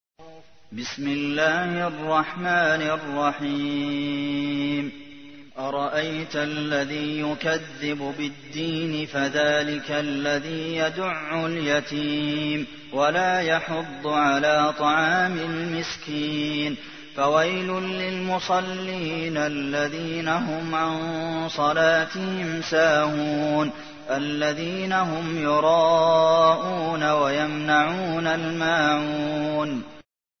تحميل : 107. سورة الماعون / القارئ عبد المحسن قاسم / القرآن الكريم / موقع يا حسين